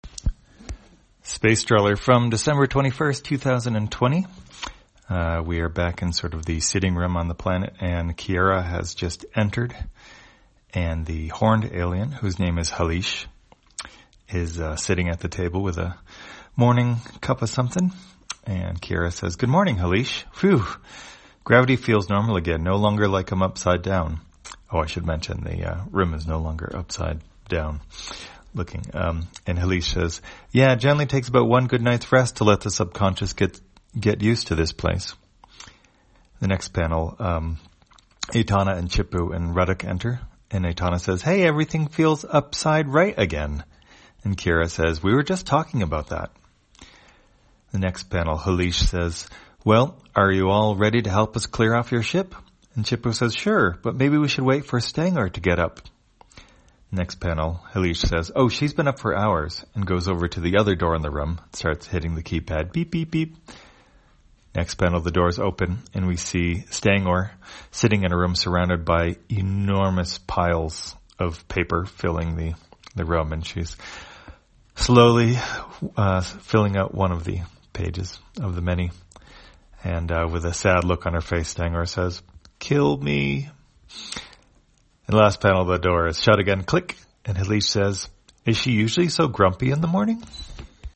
Spacetrawler, audio version For the blind or visually impaired, December 22, 2020.